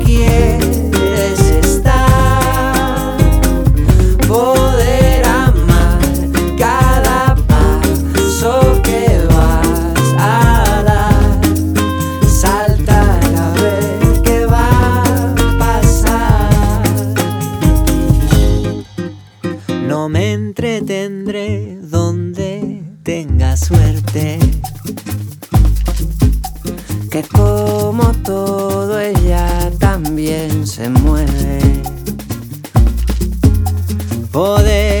# Brazilian